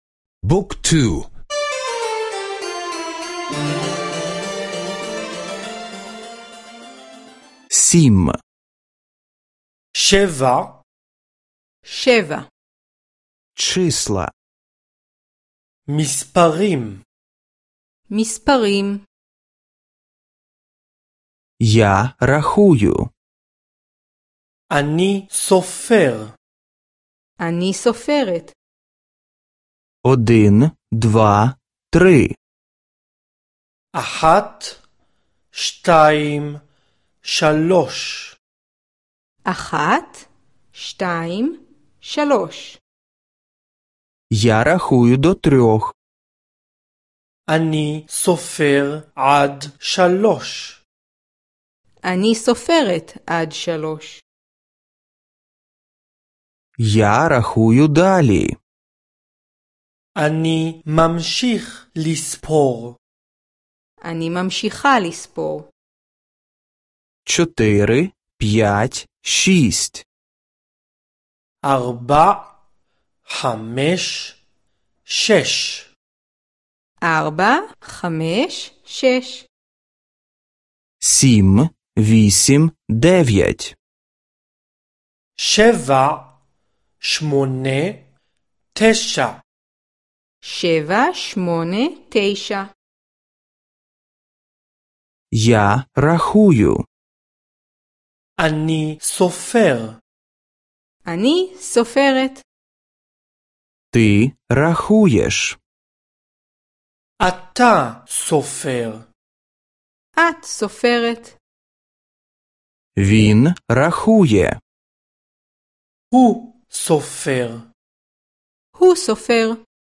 Аудіо уроки мови івриту — завантажити безкоштовно